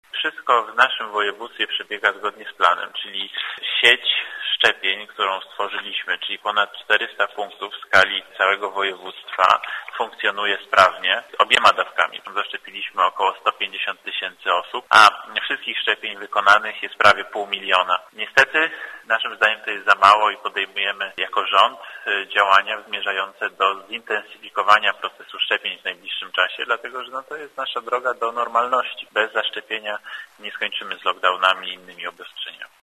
Od kilku miesięcy trwają szczepienia przeciw COVID-19. To jest nasza droga do normalności, bez zaszczepienia nie skończymy z lockdownem i innymi obostrzeniami – mówi wojewoda łódzki, Tobiasz Bocheński, który zapewnia, że proces szczepień w województwie łódzkim idzie zgodnie z planem.